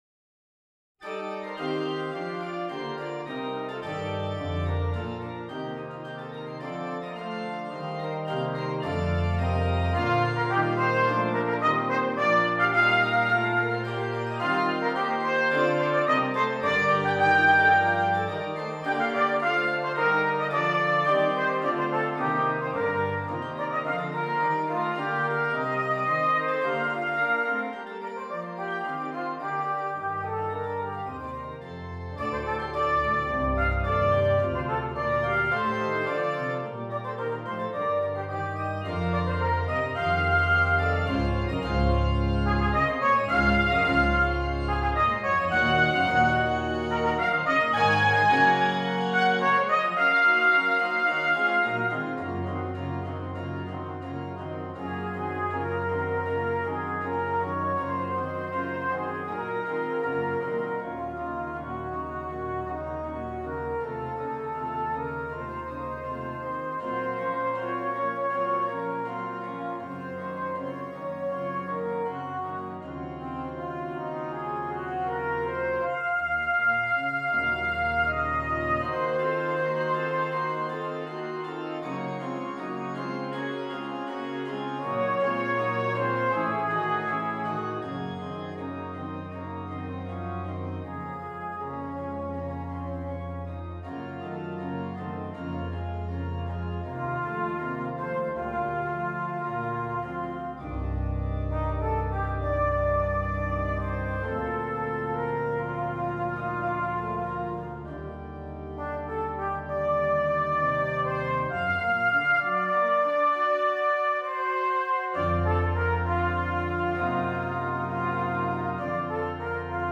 Trumpet and Keyboard
for solo trumpet